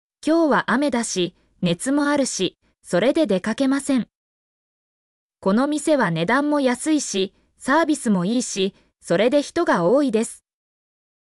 mp3-output-ttsfreedotcom-41_0ZpjqmRX.mp3